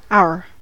our: Wikimedia Commons US English Pronunciations
En-us-our.WAV